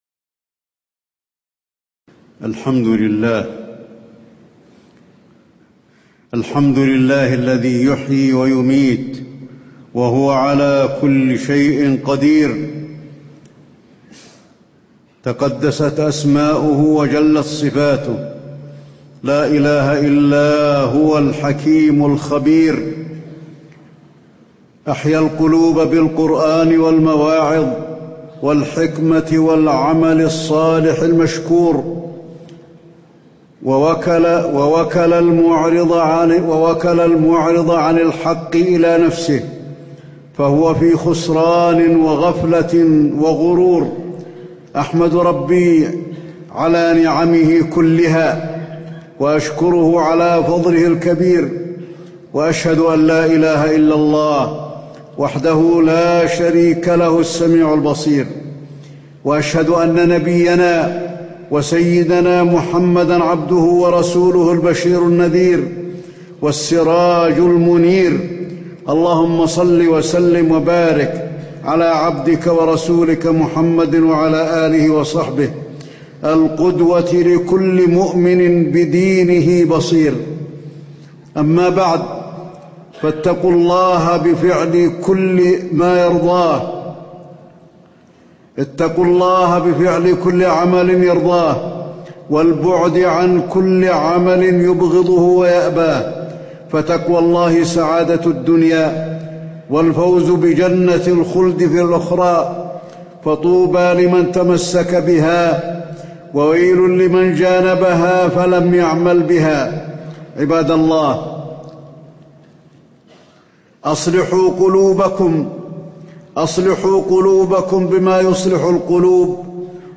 تاريخ النشر ٣ ربيع الأول ١٤٣٨ هـ المكان: المسجد النبوي الشيخ: فضيلة الشيخ د. علي بن عبدالرحمن الحذيفي فضيلة الشيخ د. علي بن عبدالرحمن الحذيفي التحذير من الغفلة